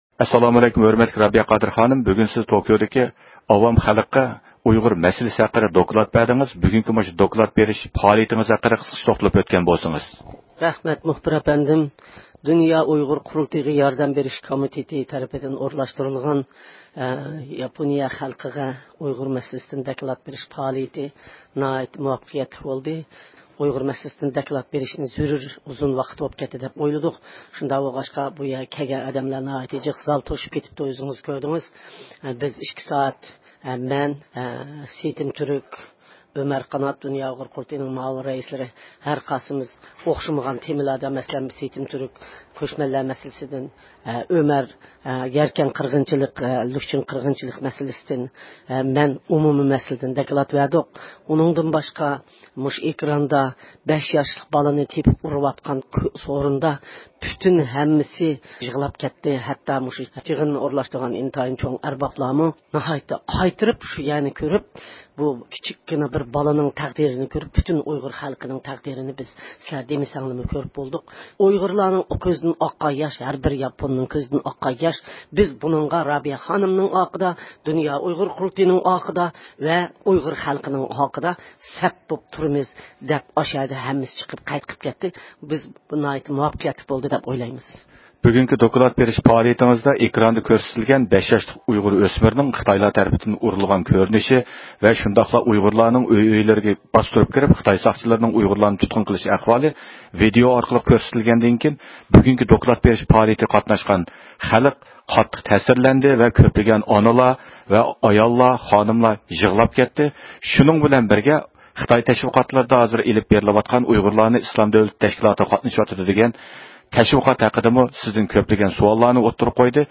بىز يىغىن ئاخىرلاشقاندىن كېيىن مەزكۇر دوكلات سۆزلەش يىغىنى ھەققىدە تولۇق مەلۇماتقا ئېرىشىش ئۈچۈن دۇنيا ئۇيغۇر قۇرۇلتىيىنىڭ رەئىسى رابىيە قادىر خانىمغا مىكروفونىمىزنى ئۇزاتتۇق.